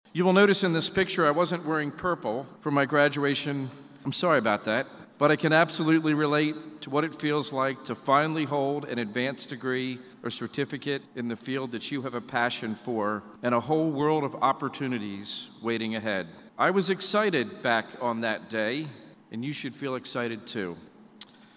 K-State President Richard Linton delivered the commencement speech to the Graduate School  Friday afternoon at Bramlage Coliseum.